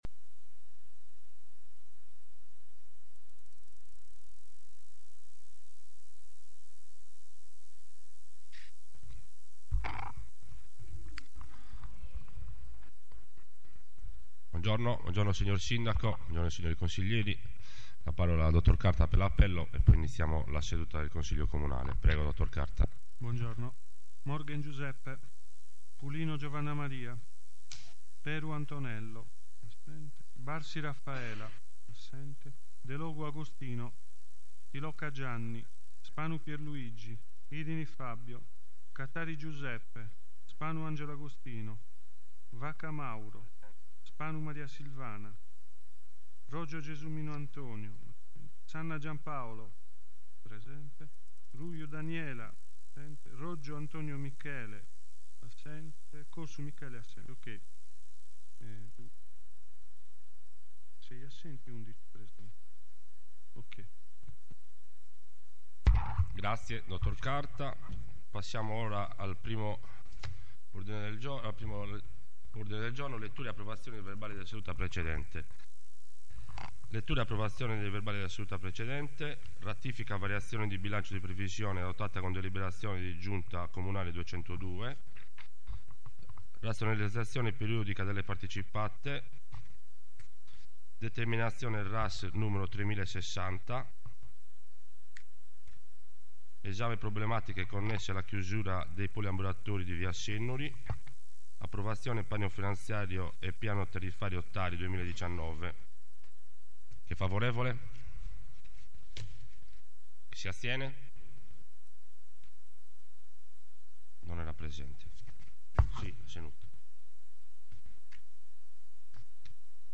Comune di Sorso (SS) - Audio Consiglio Comunale 2019
Consiglio Comunale del 10 Apriel 2019.MP3